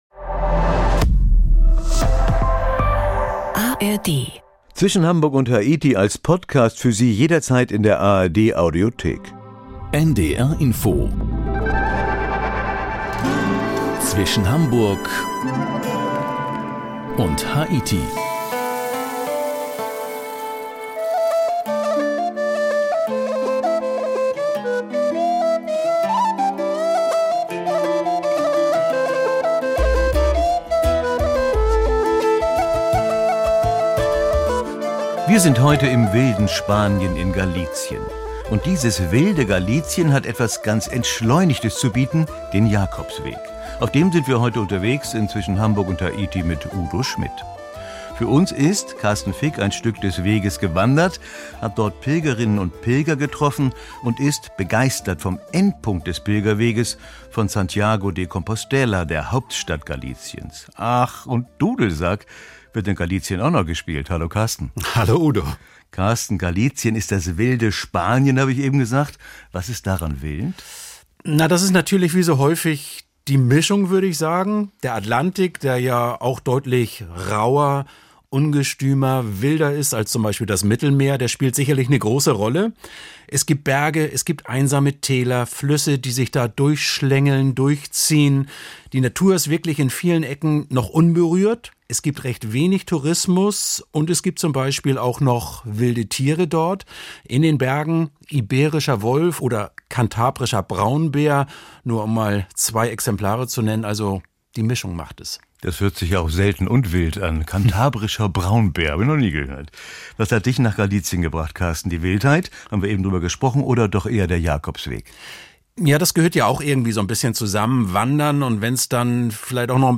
Reportagen